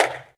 step.ogg